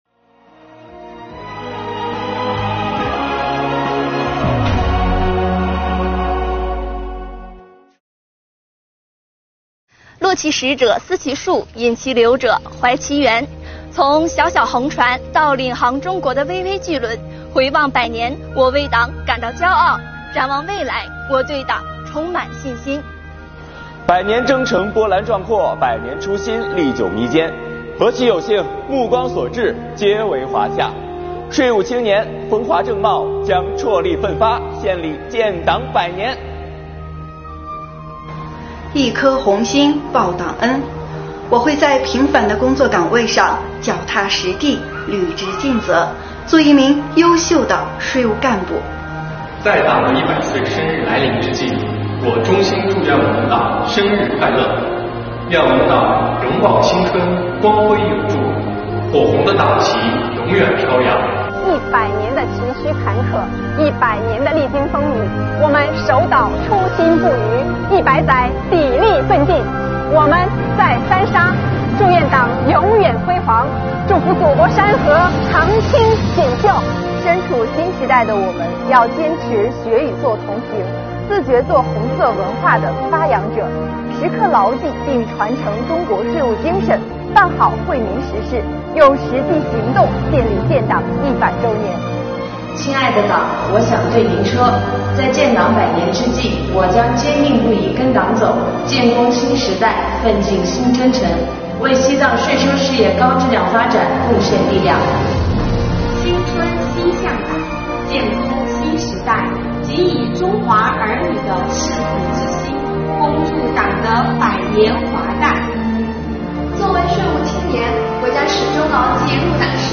今天是建党100周年纪念日，税务系统广大党员干部面对镜头，为党送上诚挚的祝福。
作为新时代的税务青年